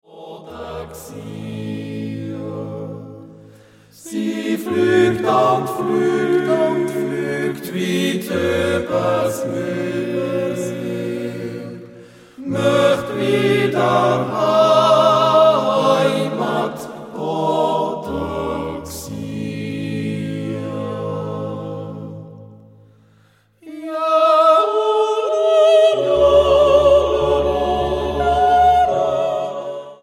Genre: Chormusik / Jodel
Genre / Stil: Chor